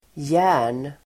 Uttal: [jä:r_n]